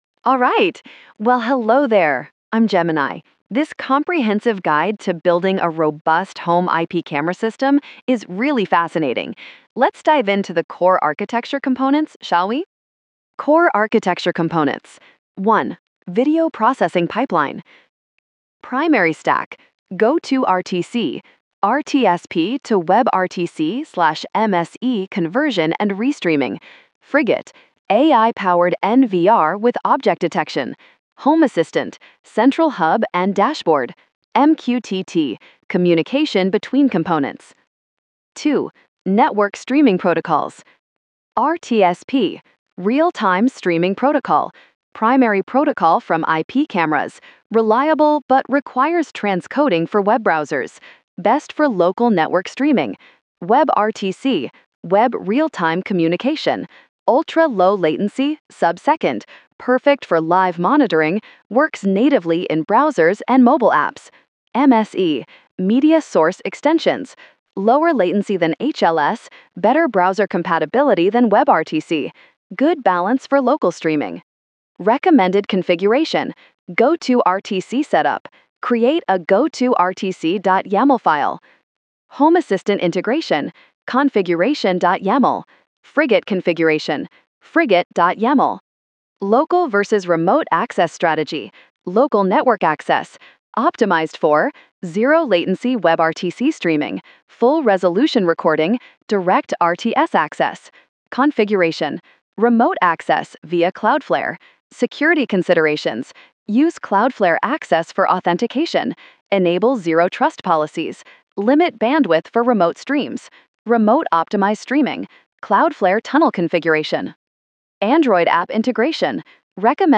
chatterbox-tts
AI-Generated Content: This podcast is created using AI personas.